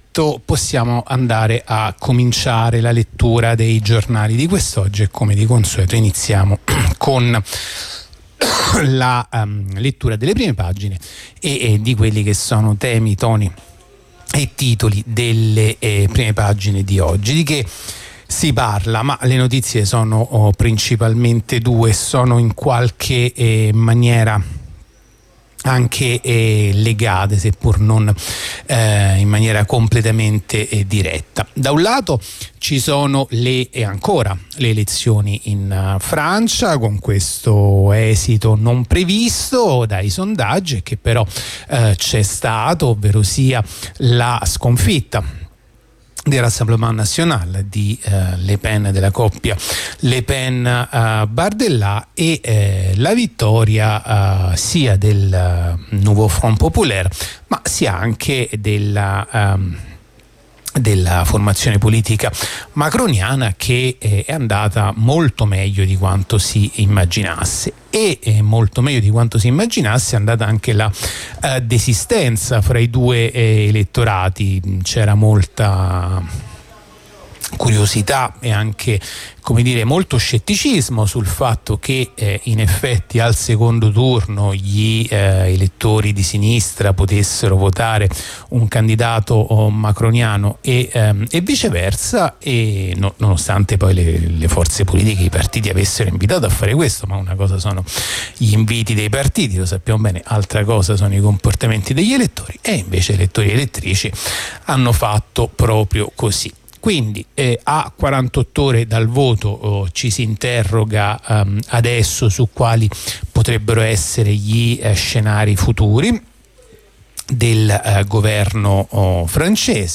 La rassegna stampa di radio onda rossa andata in onda martedì 9 luglio 2024